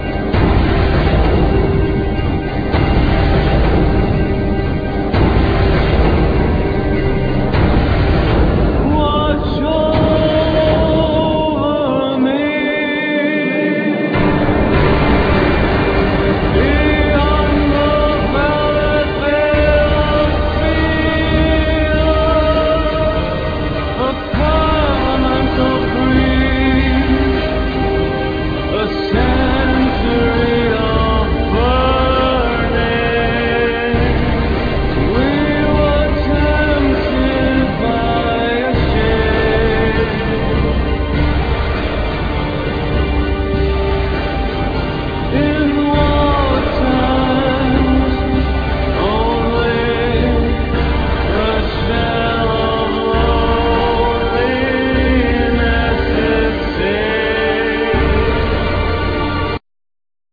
All insturuments, vocals, sound-design, programming
Trumpet, Horn, Trombone
Soprano
Violin, Viola
Violin, Solo violin